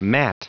Prononciation du mot mat en anglais (fichier audio)
Prononciation du mot : mat